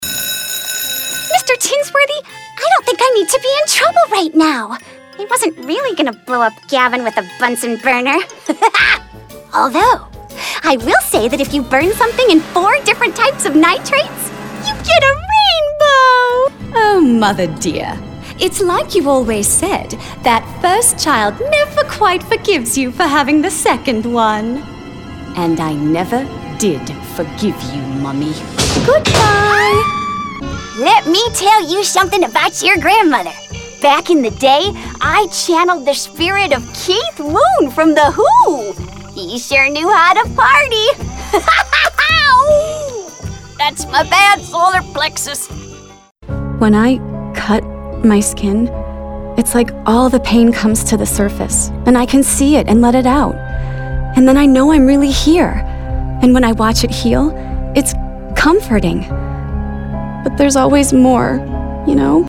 Young Adult, Adult
british rp | natural
southern us | natural
standard us | natural
ANIMATION 🎬